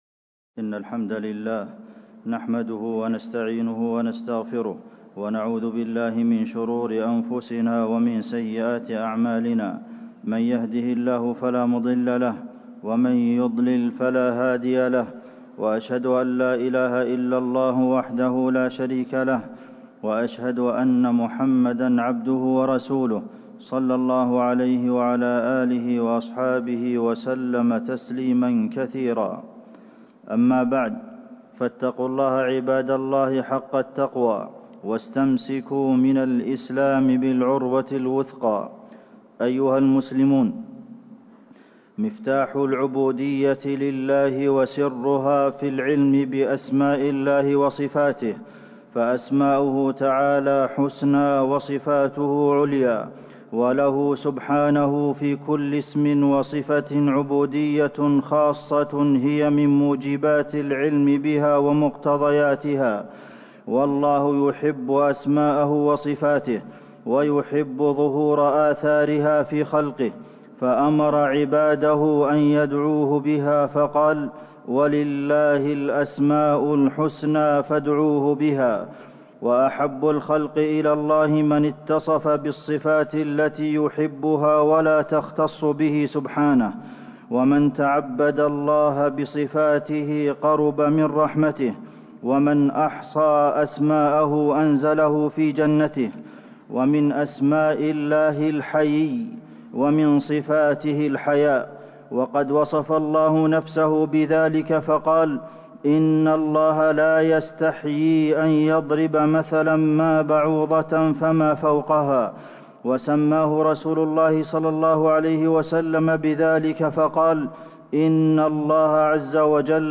فضل الحياء - خطبة الجمعة من المسجد النبوي الشريف - الشيخ عبد المحسن القاسم